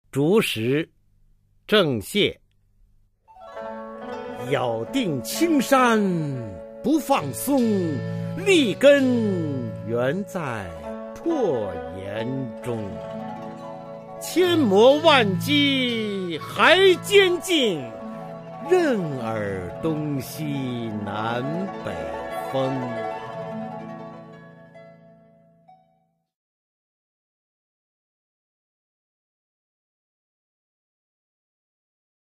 [清代诗词诵读]郑板桥-竹石 配乐诗朗诵